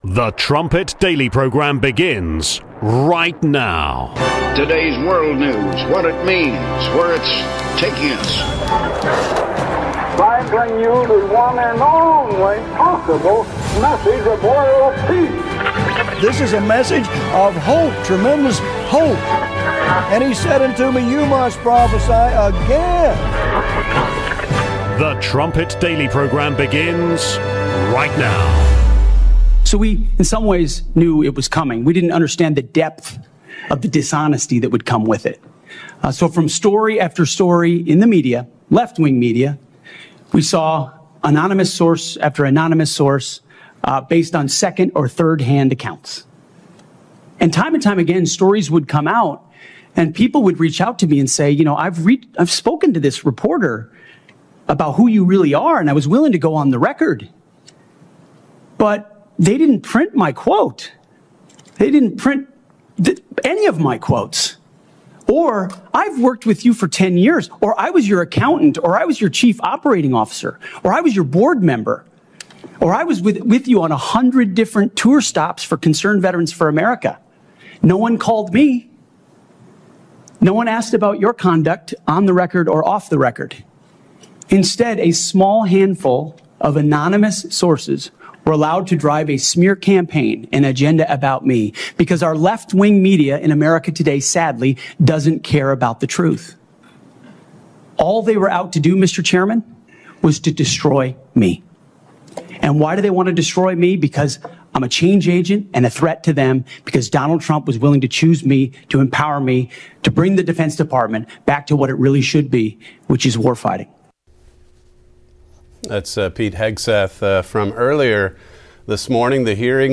47:00 Celtic Throne Interview and Feedback (8 minutes)